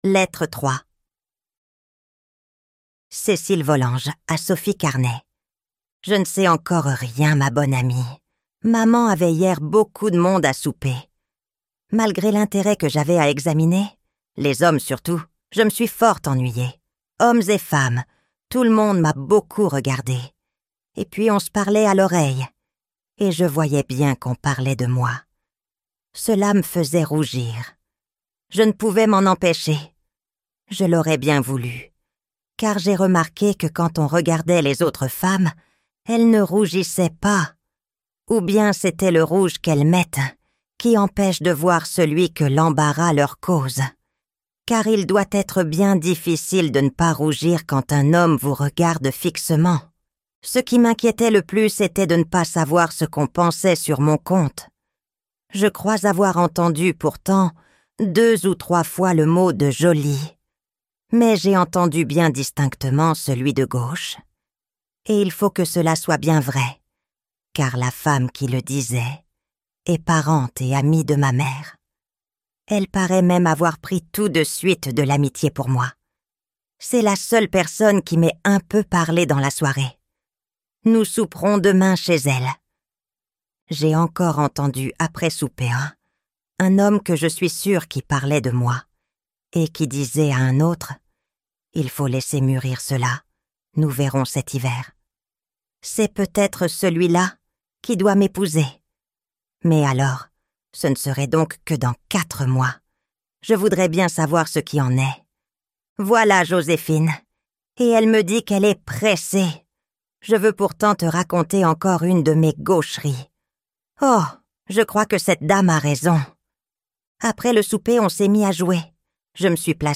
Les liaisons dangereuses - Livre Audio
Extrait gratuit